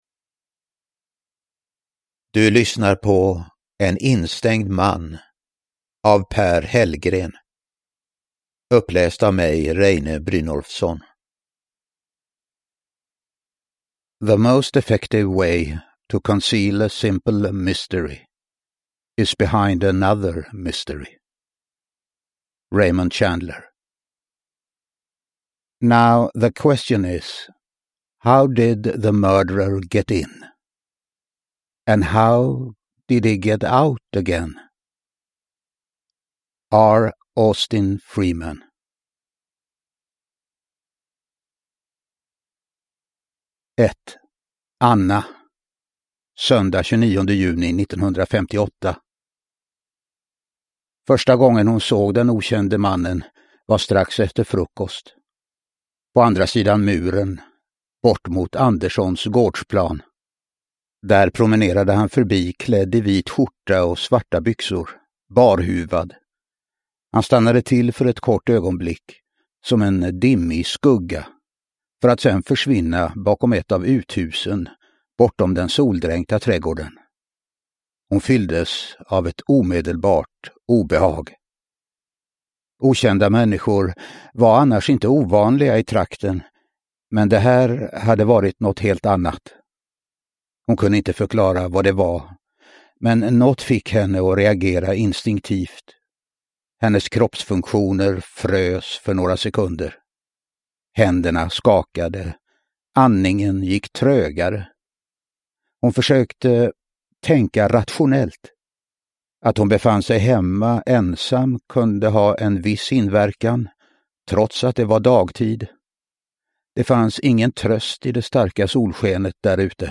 En instängd man – Ljudbok – Laddas ner
Uppläsare: Reine Brynolfsson